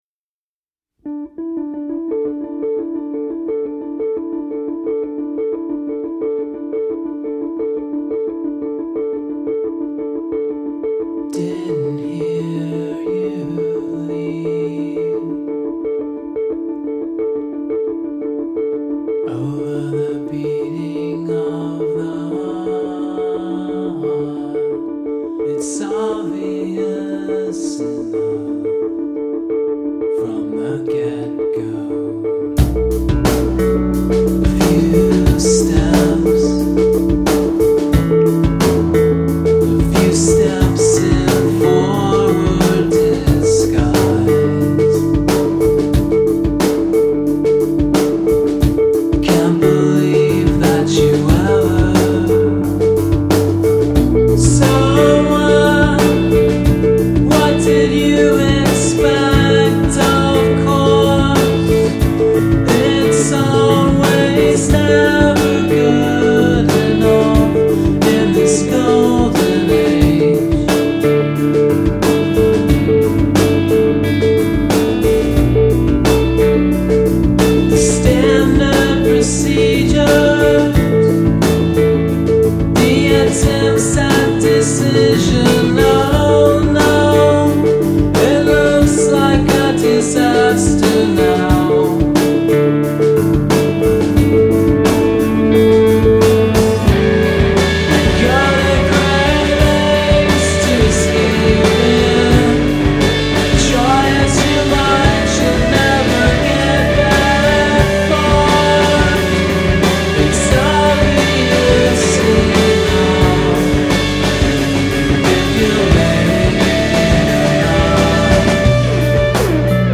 runs through prickly guitar lines